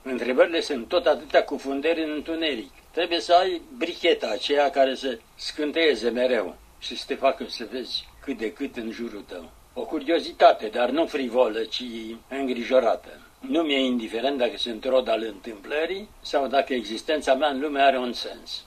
Într-o intervenţie la Radio România Cultural, după ce împlinise 106 ani, Mihai Şora încă îşi punea întrebări: